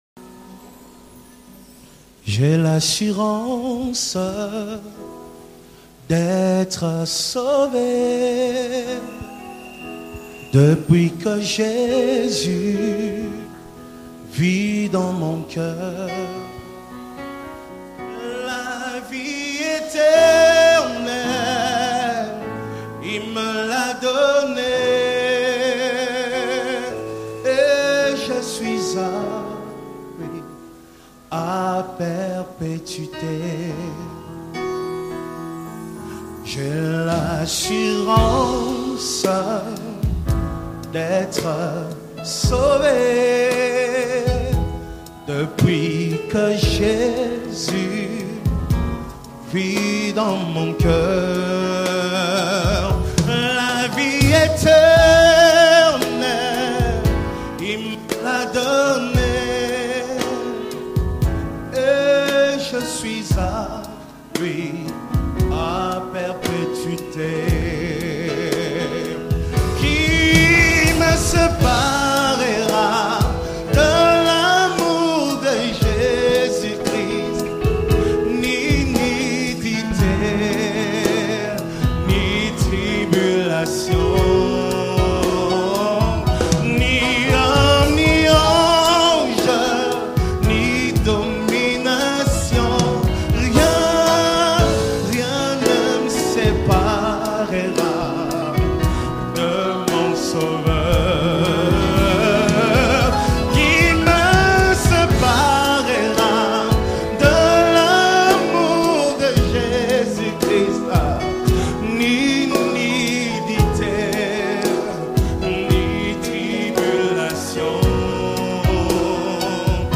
SPIRIT-FILLED WORSHIP ANTHEM